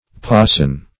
pasan - definition of pasan - synonyms, pronunciation, spelling from Free Dictionary Search Result for " pasan" : The Collaborative International Dictionary of English v.0.48: Pasan \Pa"san\, n. (Zool.)